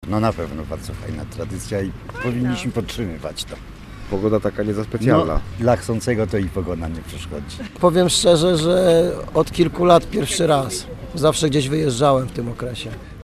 Ponad tysiąc osób wzięło udział w Orszaku Trzech Króli w Słupsku.
Niektórzy słupszczanie biorą w orszakach udział od lat, inni – dopiero się do tego przekonują: